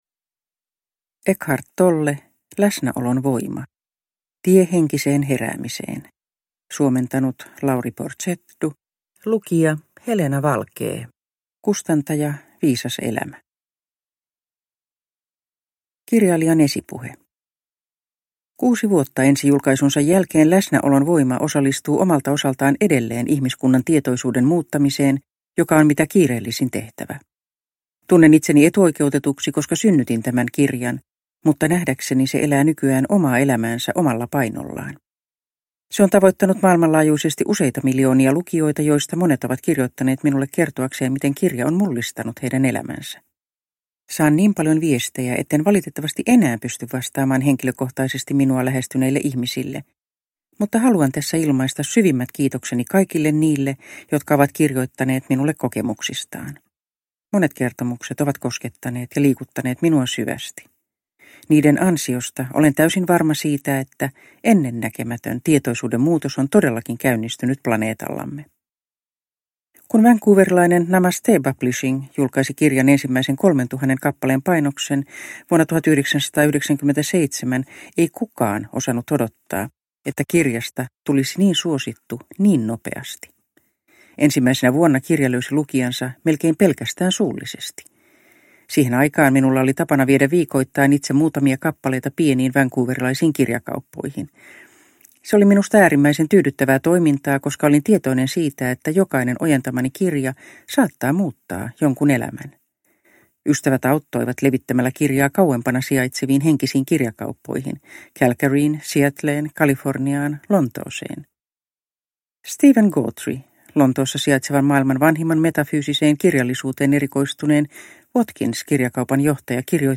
Läsnäolon voima – Ljudbok – Laddas ner